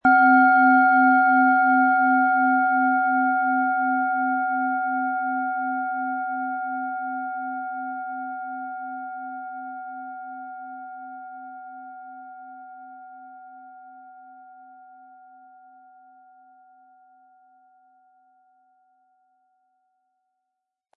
Antike Klangschalen – Schätze der Klarheit
Ihr Klang ist klar, fein und zugleich tief – er entfaltet sich wie ein inneres Licht und schenkt dir das Gefühl von Ordnung, Zentrierung und innerer Verbundenheit.
Diese Schale schwingt im Ton der DNA-Frequenz – einer Schwingung, die deiner inneren Struktur und Ordnung zugeordnet wird.
Um den Original-Klang genau dieser Schale zu hören, lassen Sie bitte den hinterlegten Sound abspielen.
MaterialBronze